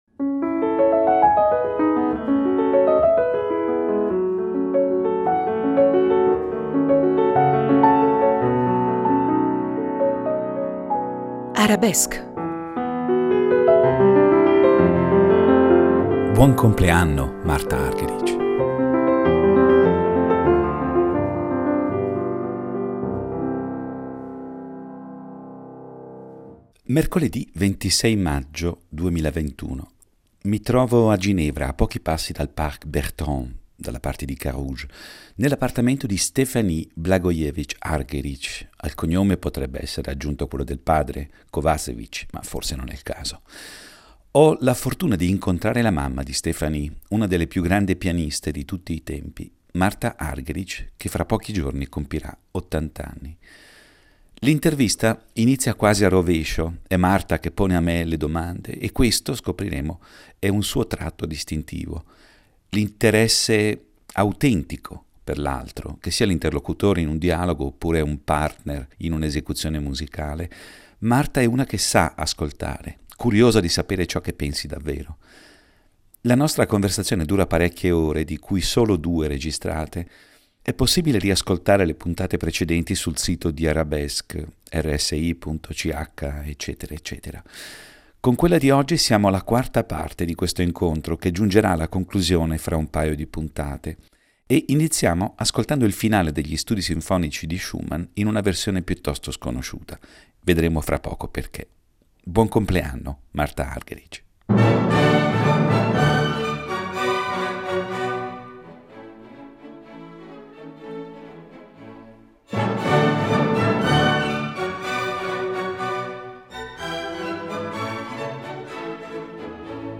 In questa puntata di «Arabesque» proseguiamo l’ascolto della conversazione con la pianista argentina.